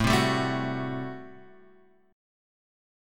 A Minor 13th